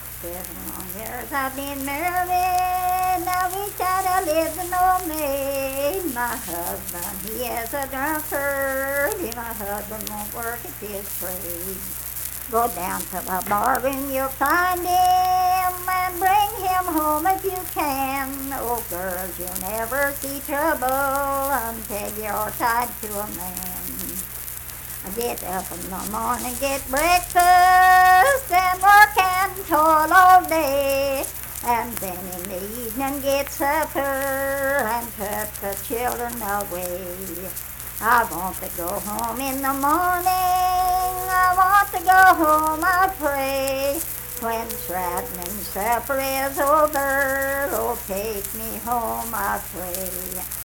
Unaccompanied vocal music performance
Verse-refrain 4(4).
Voice (sung)